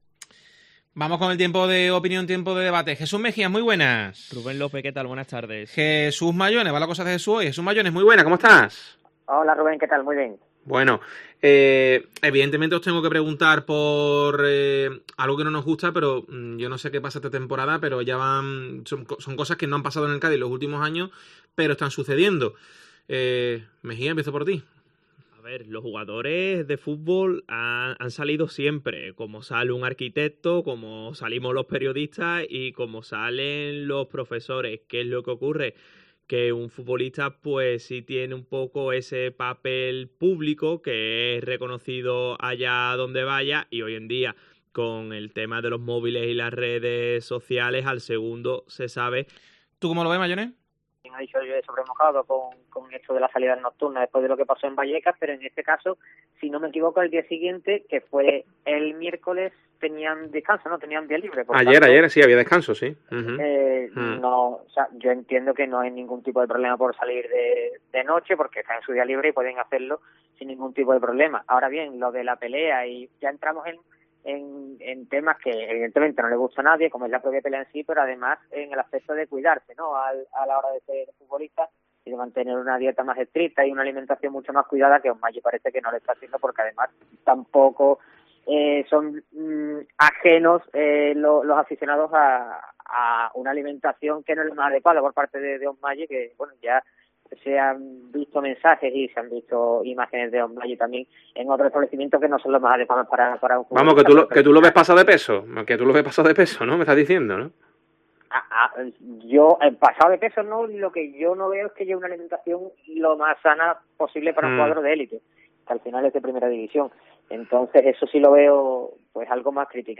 El debate sobre la actualidad del Cádiz y el caso Osmajic